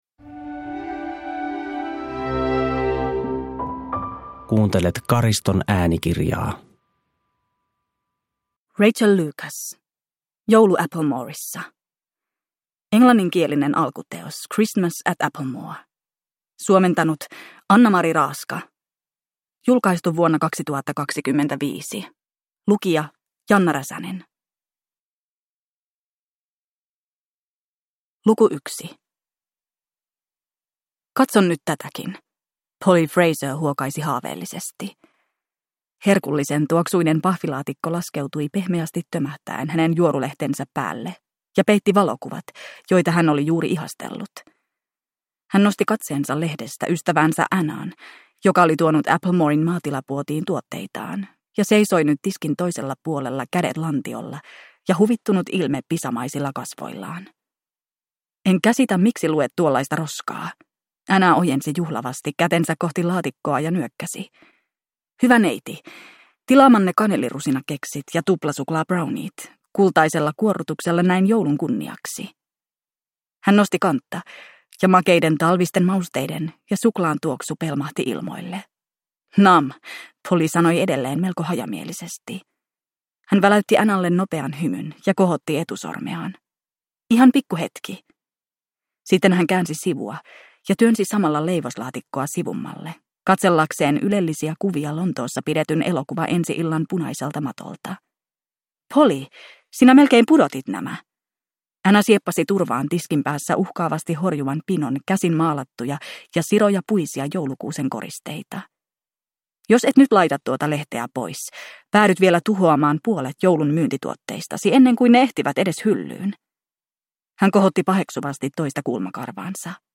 Joulu Applemoressa (ljudbok) av Rachael Lucas